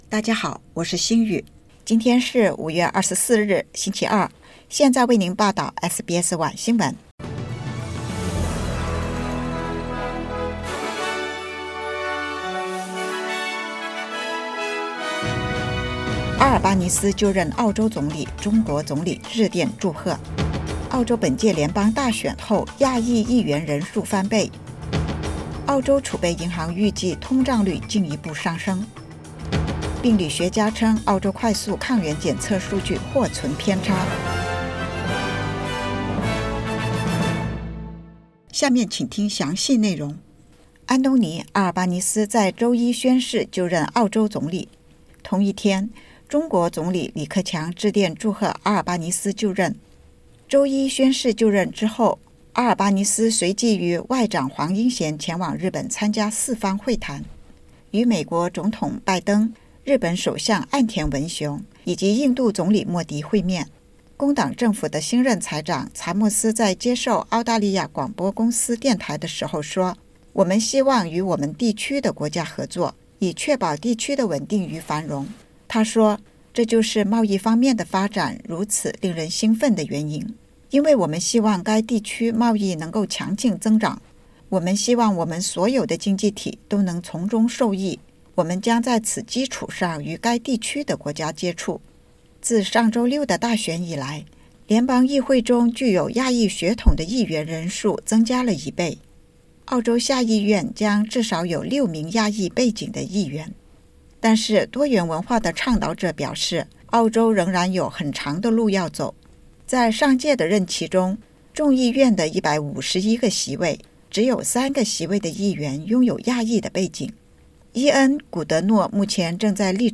SBS晚新闻（2022年5月24日）
SBS Mandarin evening news Source: Getty Images